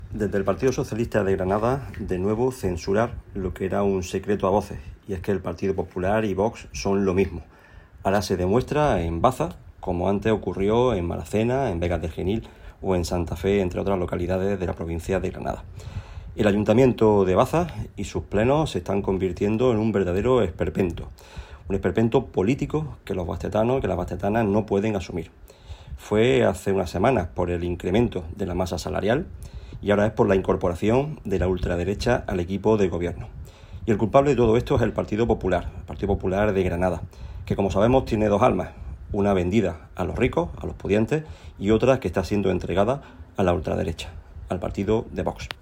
El PSOE censura la entrada de Vox en el gobierno local de Baza, que considera “una cesión más del PP a la ultraderecha”. Este es el titular del comunicado que nos ha remitido el PSOE granadino, en el que Los socialistas critican que se produzca un pleno extraordinario “en pleno mes de agosto y apenas dos meses después de tomar posesión la nueva corporación municipal”, el cual reproducimos a continuación, en su integridad, junto a la imagen y la nota de voz que lo acompañan: